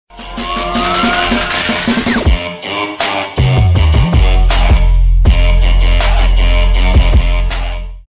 dubstepn sound button Download
dubstep_FohnkB5.mp3